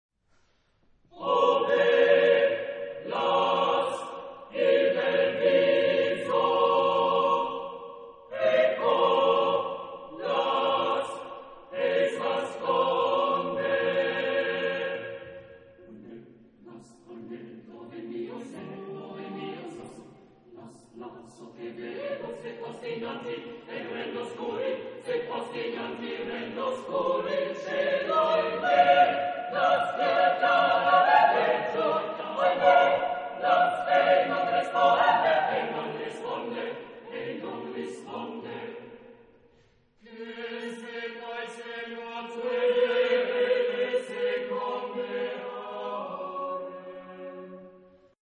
Genre-Style-Forme : Profane ; Chanson d'amour ; Madrigal
Type de choeur : SATB divisi  (4 voix mixtes )